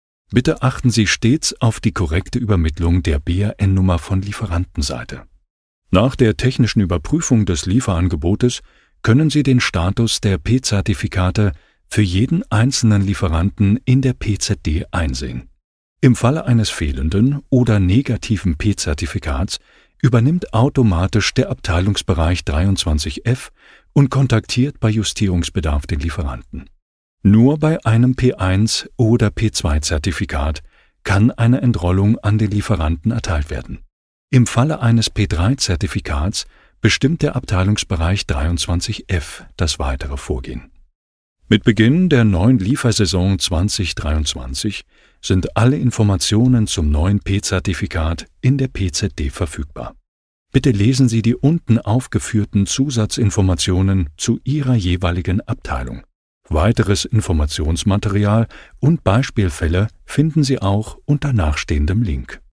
Technisch